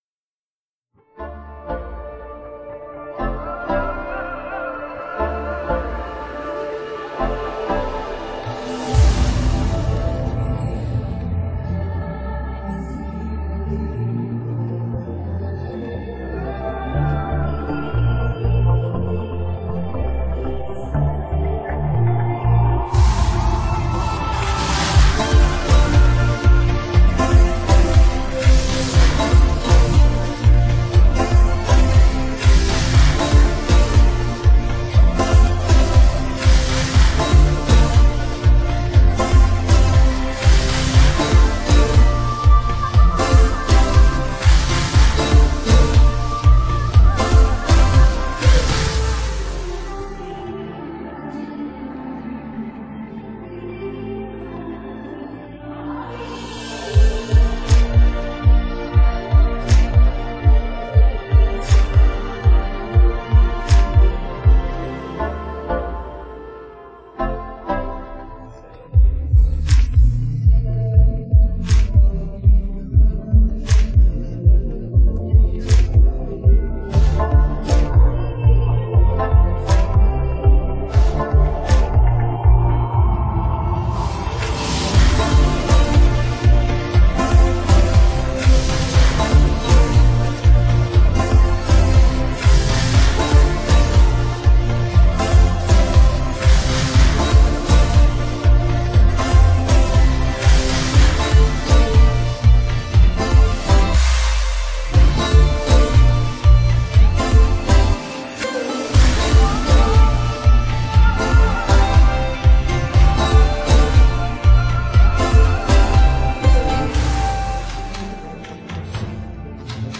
Play Karaoke and Sing with Us